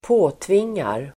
Uttal: [²p'å:tving:ar]